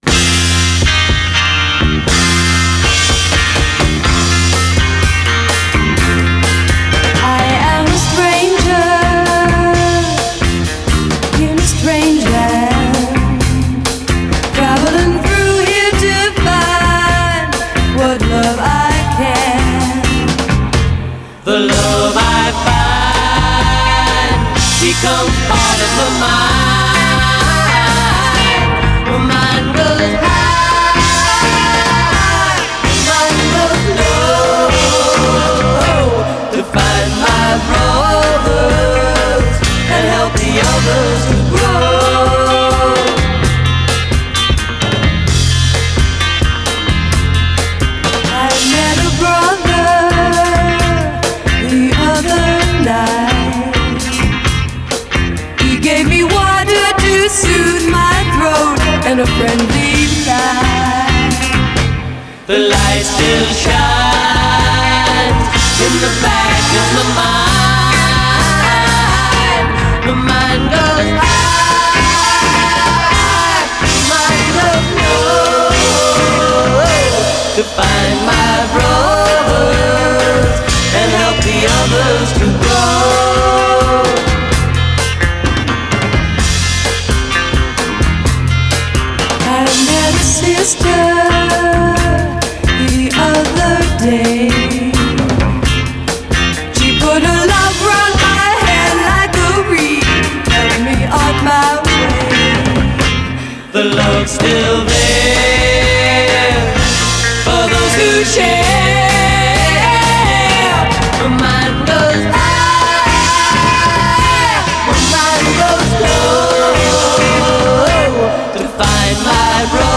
A la fin des années 60, dans le sillage de Sonny & Cher, les duos mixtes sont légion. Issus pour la plupart de la scène folk, ils embrassent l’époque et les labels leur offrent un écrin de luxe.